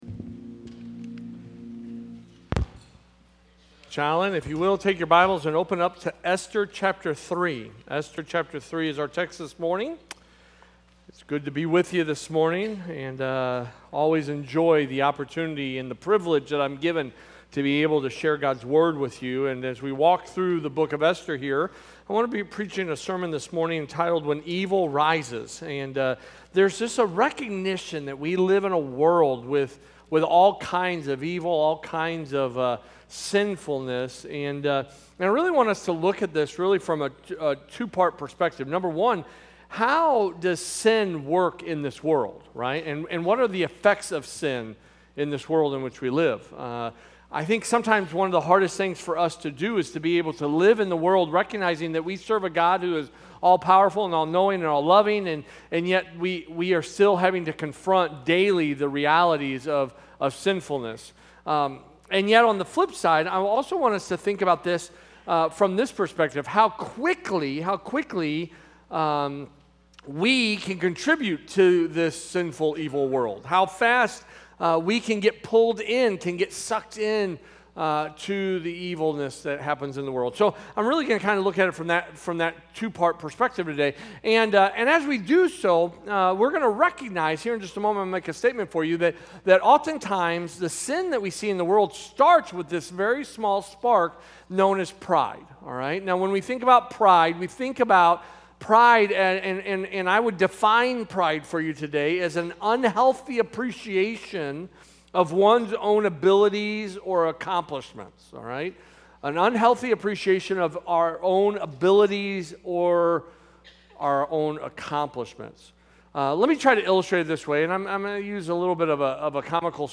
A message from the series "Esther."